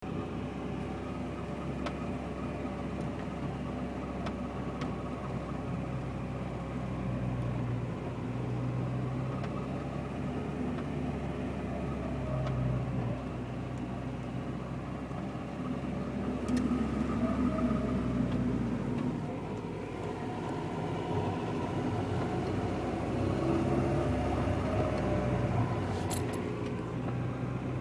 Seltsames Geräusch, brauche Hilfe - Forum: Integra
Hallo, mein Integra macht erst seit einigen Wochen ein Geräusch, und zwar beim Fahren und wie ich heute gemerkt habe auch im stand wenn ich Gas gebe, jedoch nur wenn er warm ist, wenn er kalt ist hört man es nicht. Es ist ein helles klackern, schwer - Seltsames Geräusch, brauche Hilfe - Foru...